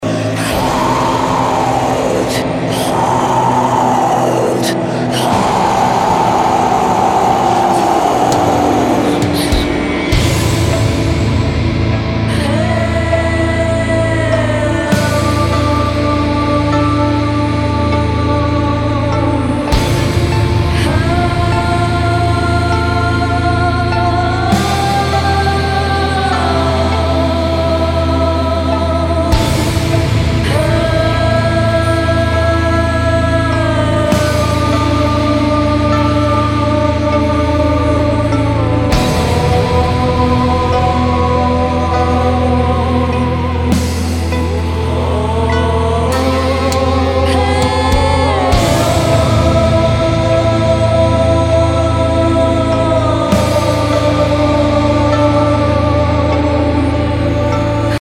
funeral doom metal